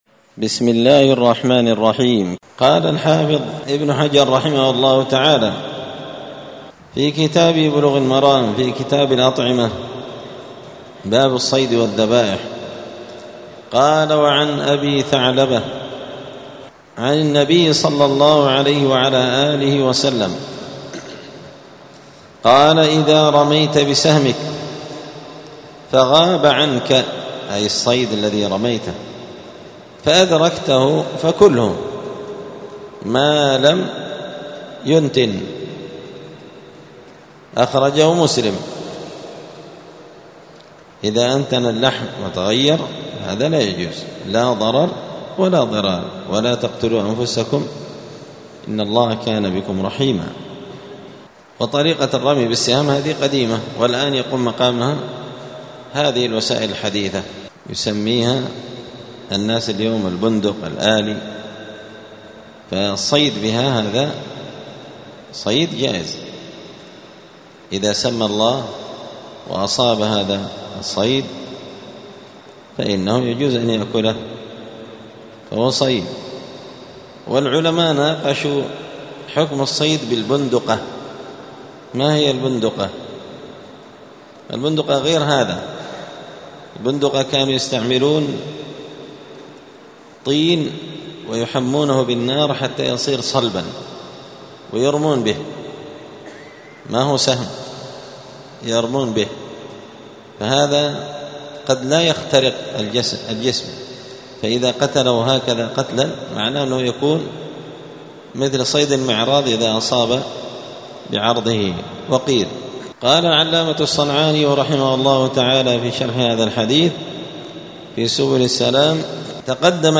*الدرس الثالث عشر (13) {باب الصيد والذبائح أكل ما أنتن من اللحم}*
دار الحديث السلفية بمسجد الفرقان قشن المهرة اليمن